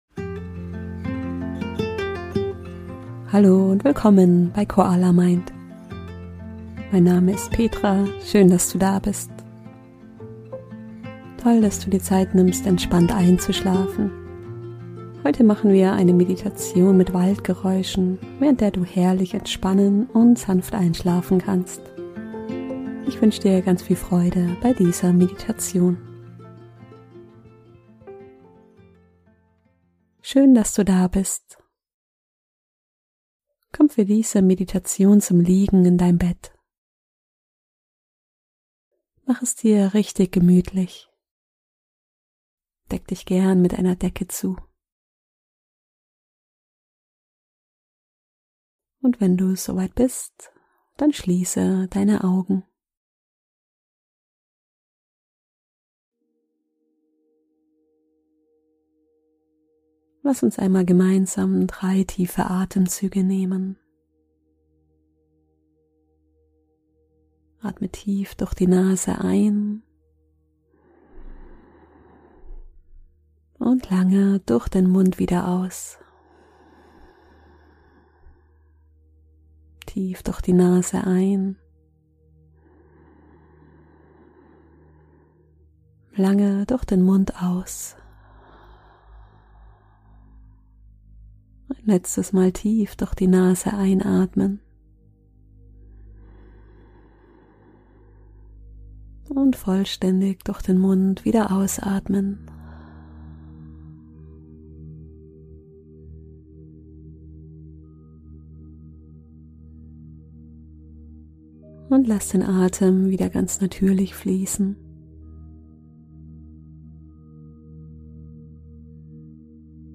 Diese Meditation begleitet dich mit angenehmen Waldgeräuschen in einen erholsamen Schlaf.
Sanftes Vogelgezwitscher macht es dir leicht, dein Nervensystem zu beruhigen und dich gedanklich an deinen Wohlfühlort zu begeben.